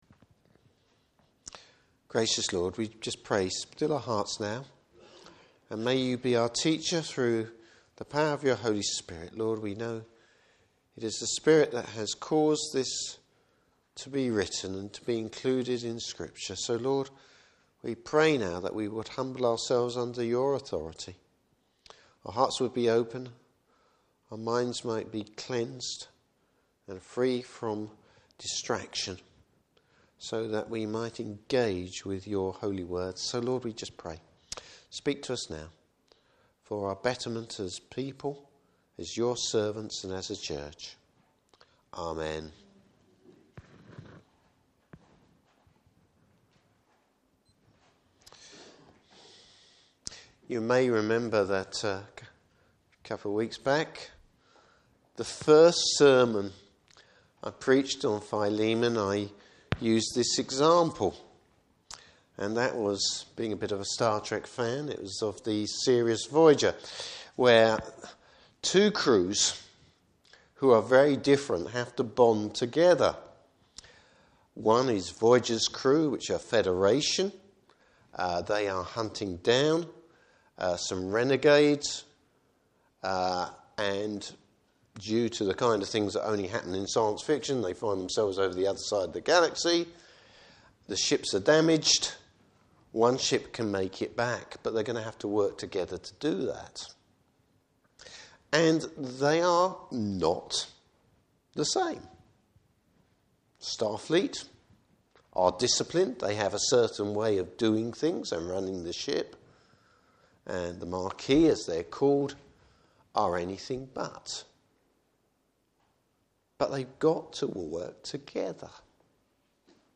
Service Type: Evening Service Bible Text: Philemon vs17-25.